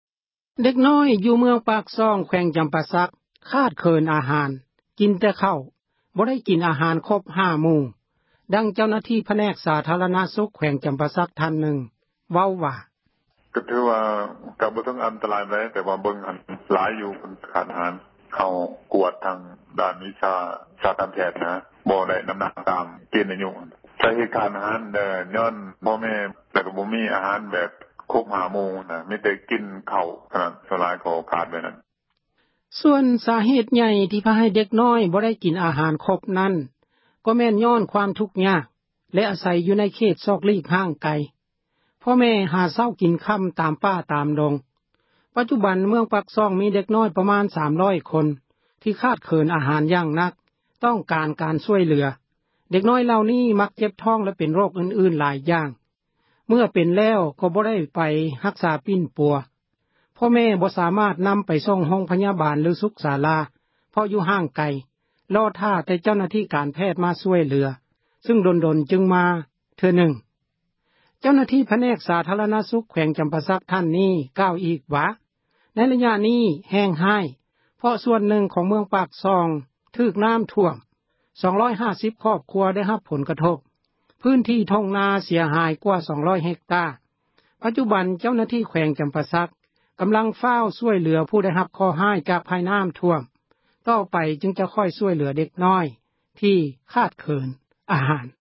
ດັ່ງເຈົ້າໜ້າທີ່ ຜແນກສາທາຣະນະສຸຂ ແຂວງຈໍາປາສັກ ທ່ານນຶ່ງເວົ້າວ່າ: